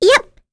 Rephy-Vox_Attack1.wav